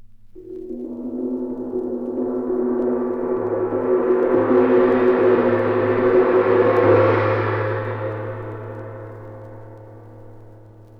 • ghostly gong sound reversed.wav
Recorded from Sound Effects - Death and Horror rare BBC records and tapes vinyl, vol. 13, 1977.
ghostly_gong_sound_reversed_Ayf.wav